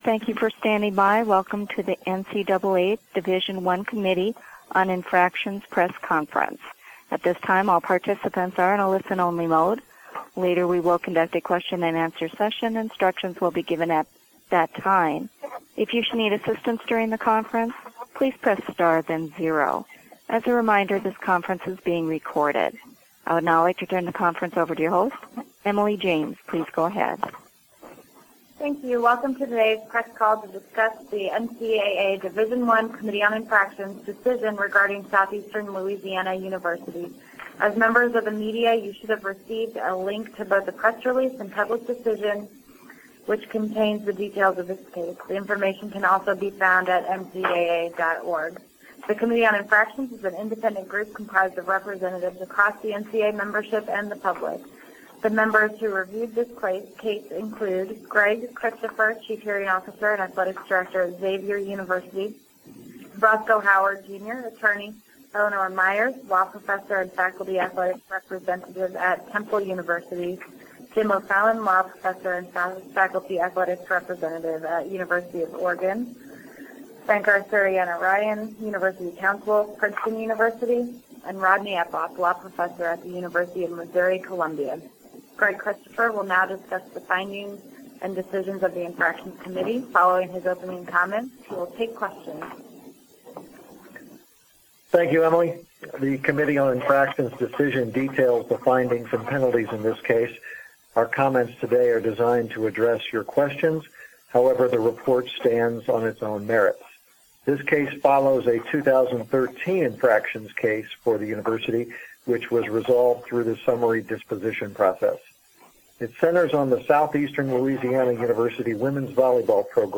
NCAA Division I Committee on Infractions Teleconference regarding Southeastern Louisiana University